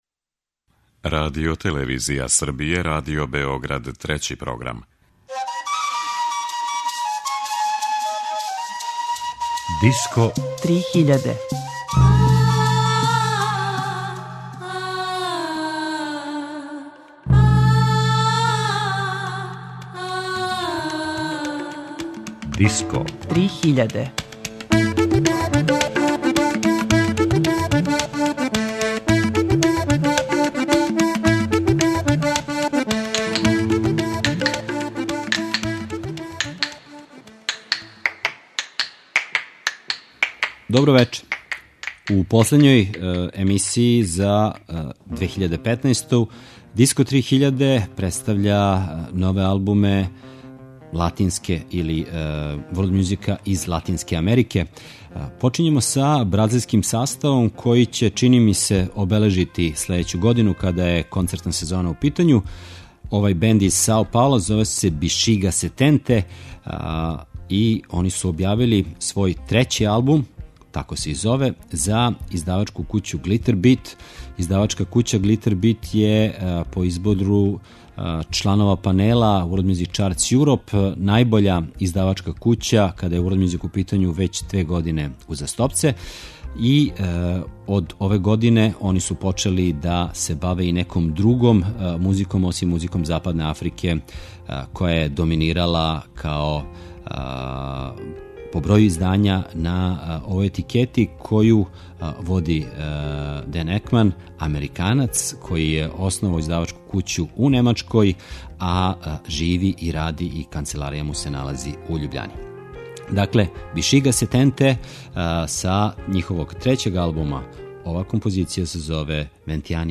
Музика Латинске Америке